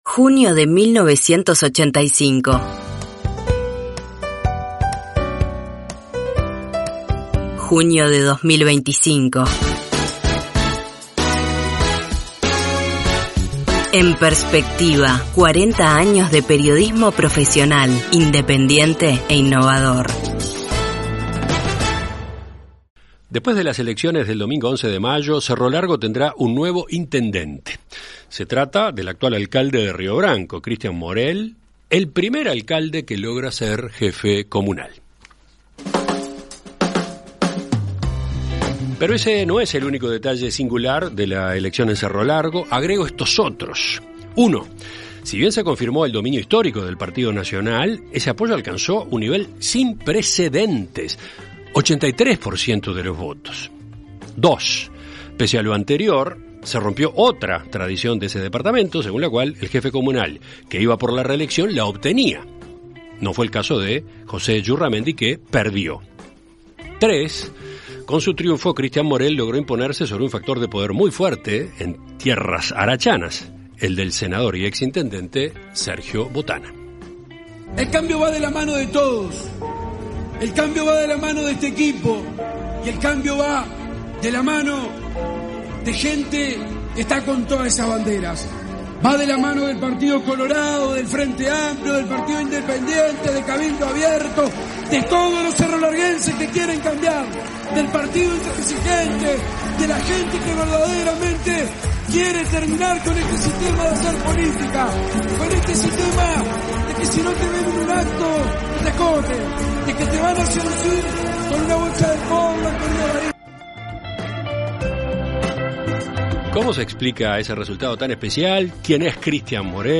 En Perspectiva Zona 1 – Entrevista Central: Christian Moriel - Océano
Conversamos con Christian Morel, intendente electo de Cerro Largo.